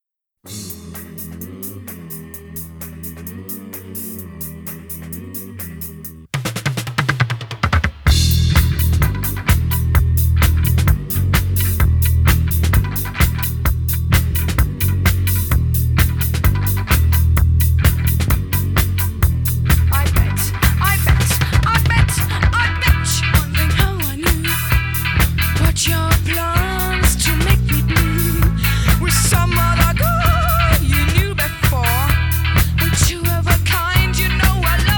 Жанр: Рок / Альтернатива / Панк